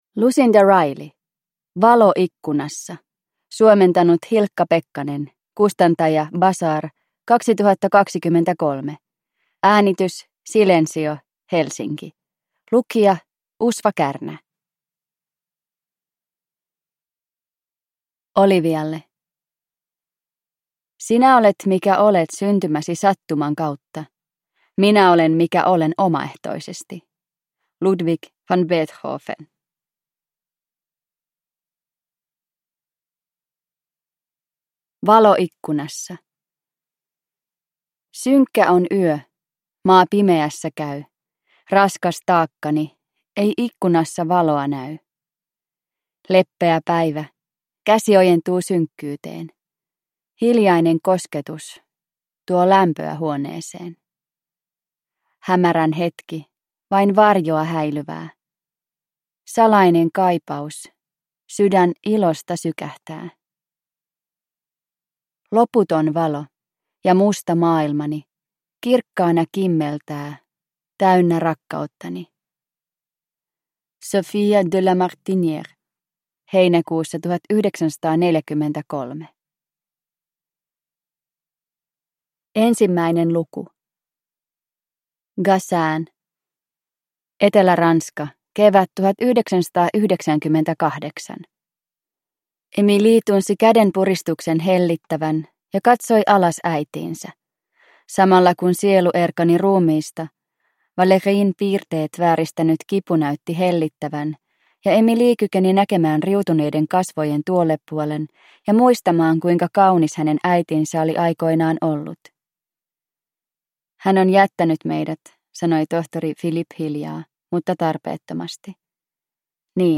Valo ikkunassa – Ljudbok – Laddas ner